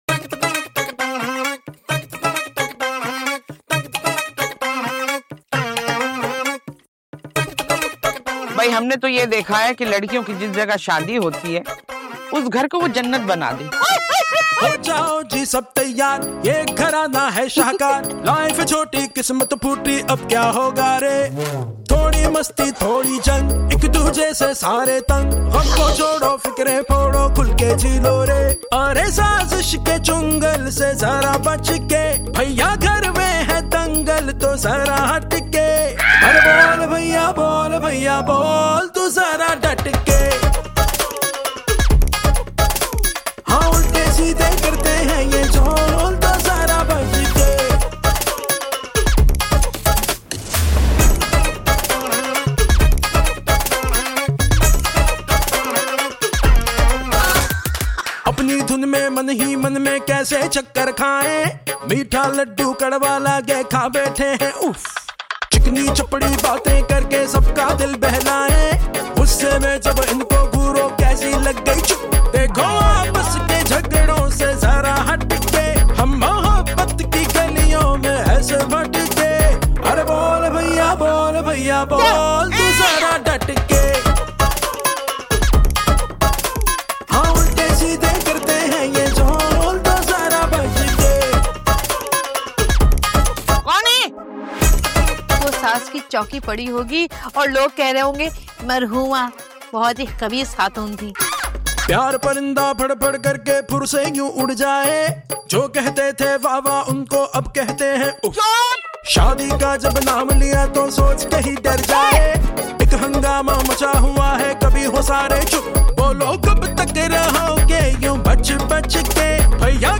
is a melody that makes everything lively